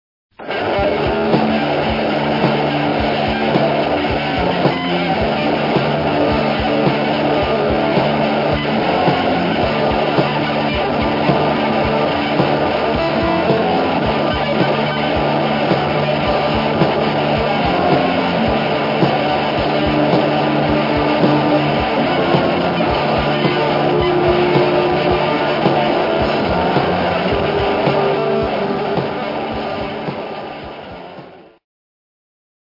もちろんアブストラクトなノイズワークもビシバシ決まった豪快な1枚！